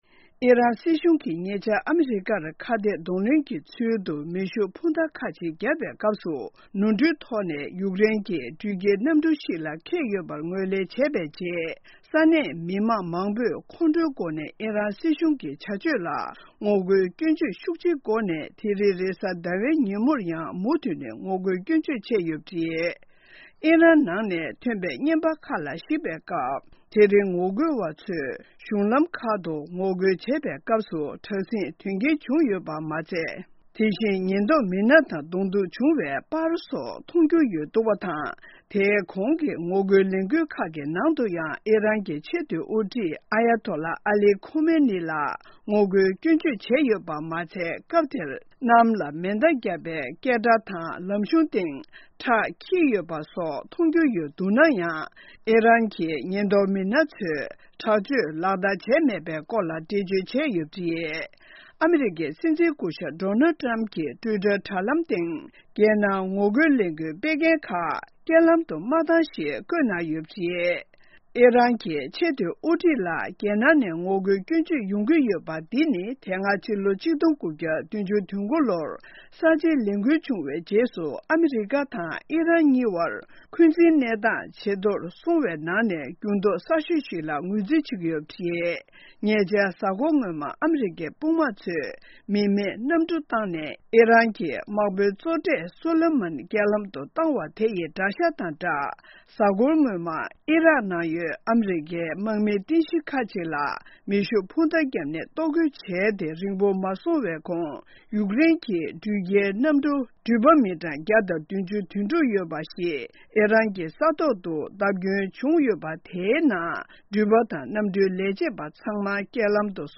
ཕབ་བསྒྱུར་དང་སྙན་སྒྲོན་ཞུ་གི་རེད།